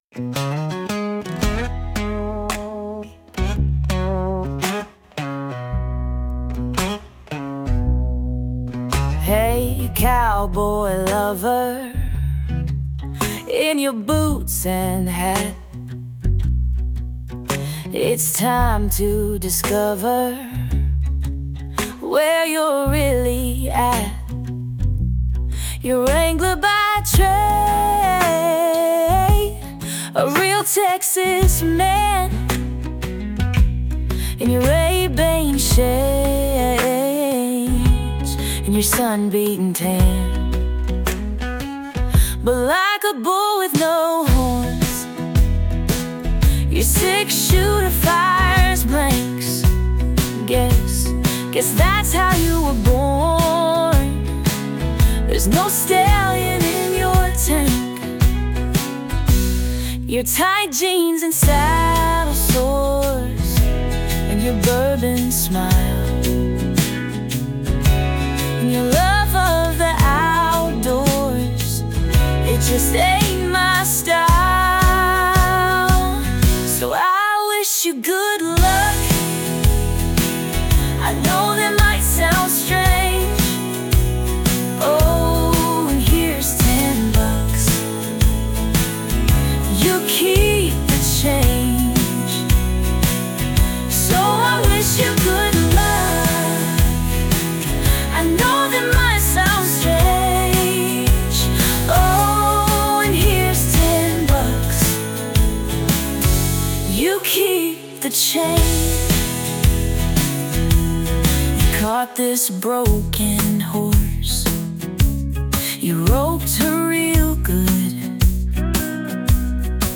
female led Country album
sharp, witty country song